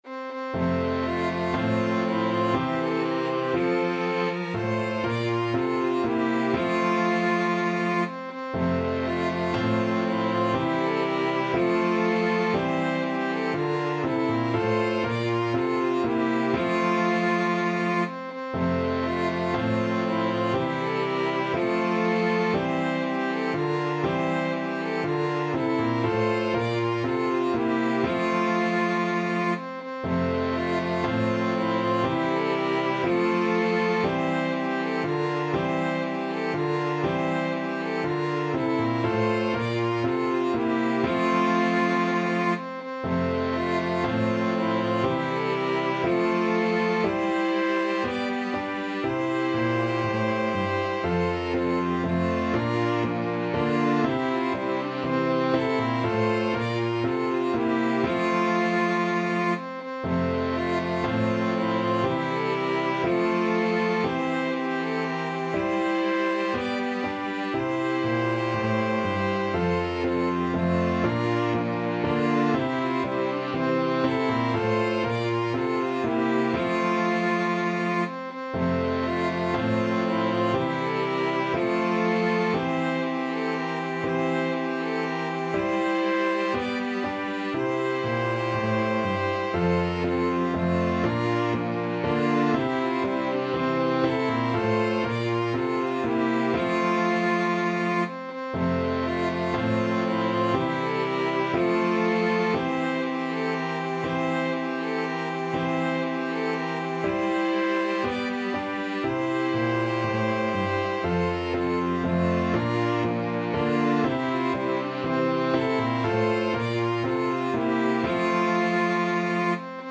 Violin 1Violin 2ViolaCelloPiano
4/4 (View more 4/4 Music)
Allegro (View more music marked Allegro)
Piano Quintet  (View more Easy Piano Quintet Music)